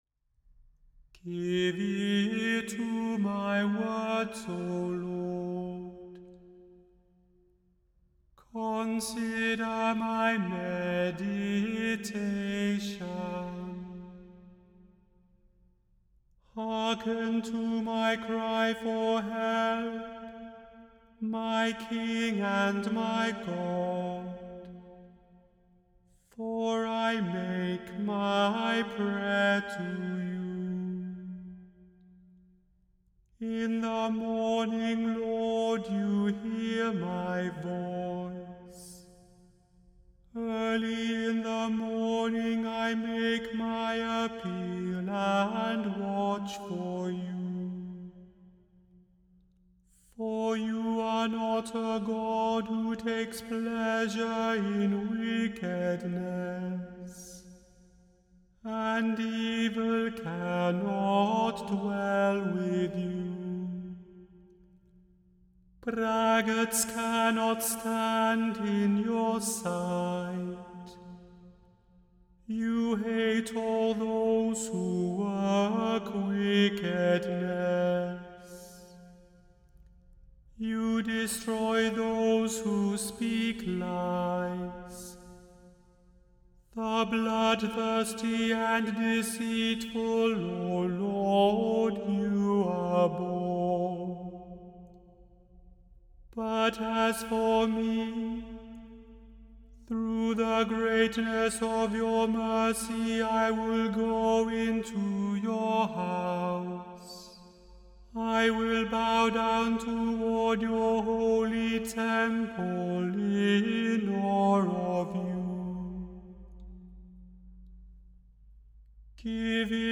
The Chant Project – Chant for Today (September 26) – Psalm 5 vs 1-7 – Immanuel Lutheran Church, New York City